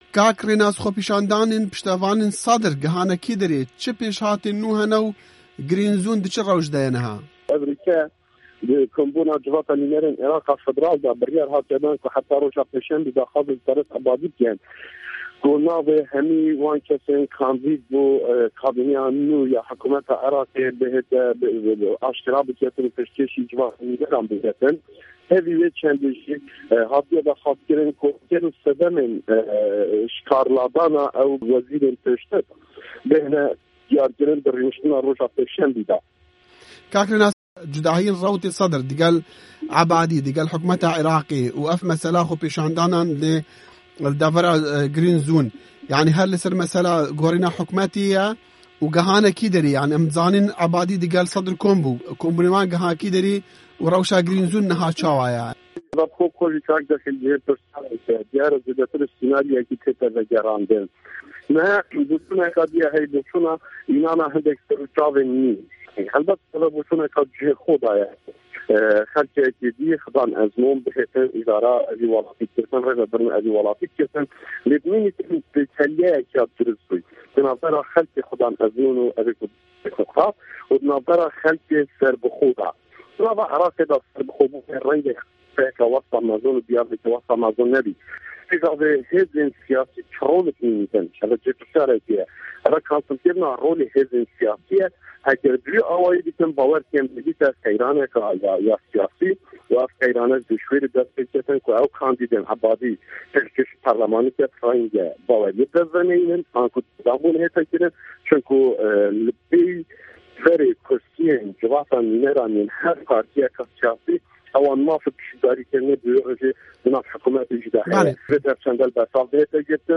Di hevpeyvînekê de Rênas Cano, endamê perlemana Îraqê biryara parlemanê dinirxîne.